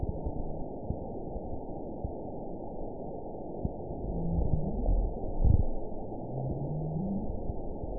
event 918934 date 12/19/23 time 22:04:47 GMT (1 year, 11 months ago) score 8.49 location TSS-AB03 detected by nrw target species NRW annotations +NRW Spectrogram: Frequency (kHz) vs. Time (s) audio not available .wav